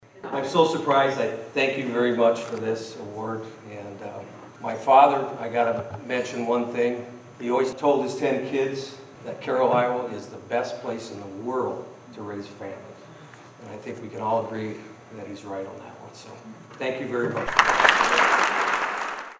Business and communities leaders met Monday night for the Carroll Chamber of Commerce Annual Banquet to celebrate the successes of 2017 and to honor citizens who made a tremendous impact over the course of the year.